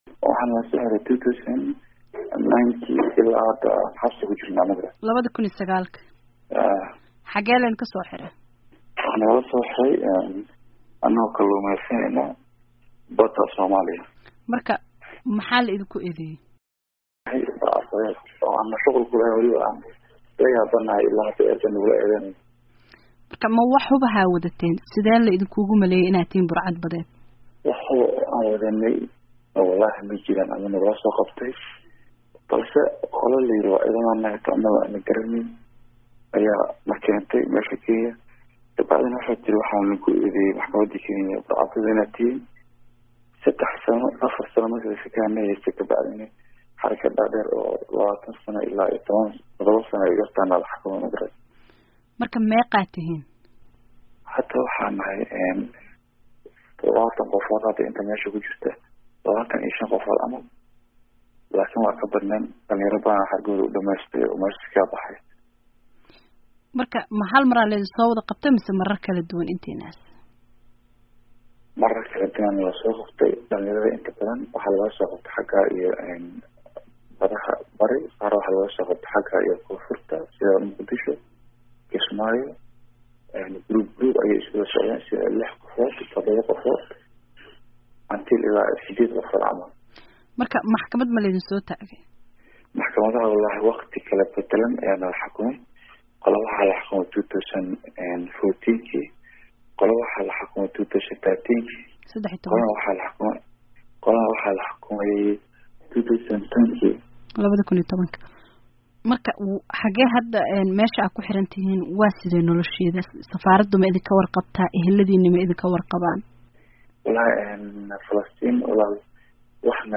Wareysi: Burcad badeed